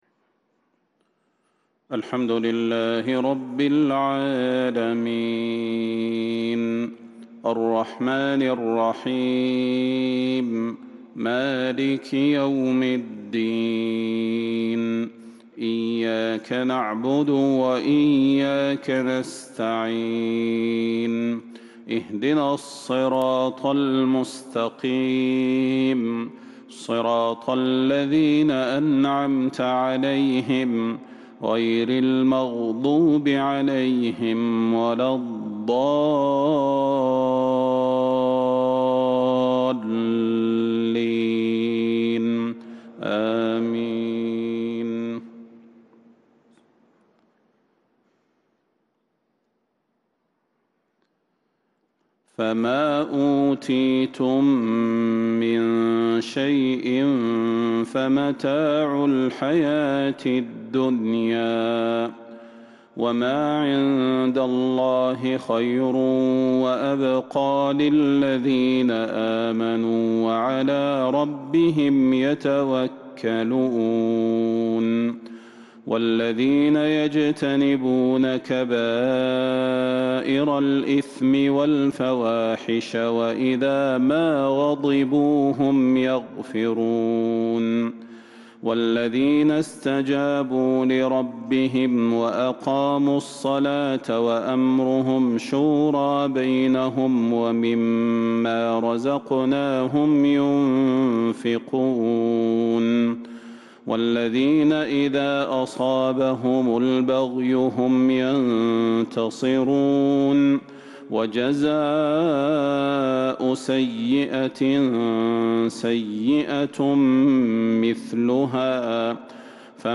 عشاء الأربعاء 21 شوال 1442هــ من سورة الشورى | Isha prayer from Surat Al-Shura 2-6-2021 > 1442 🕌 > الفروض - تلاوات الحرمين